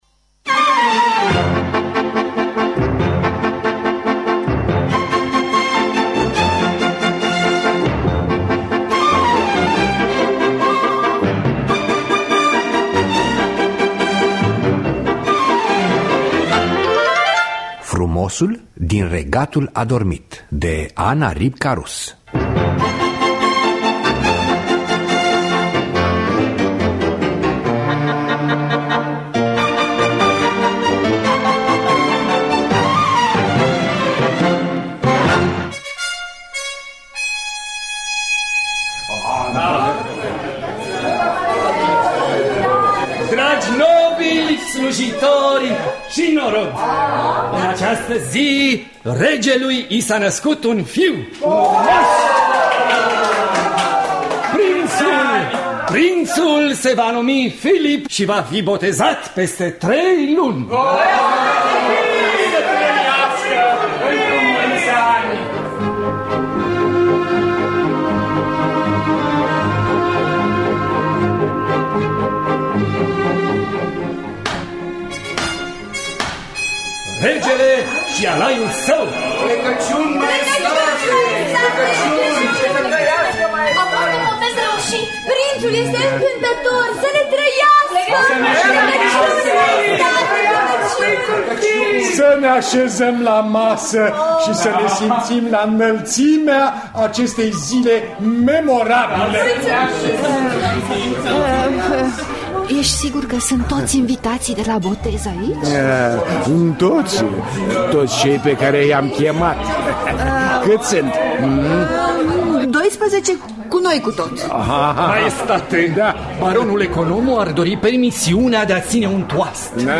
Frumosul din regatul adormit de Ana Ripka-Rus – Teatru Radiofonic Online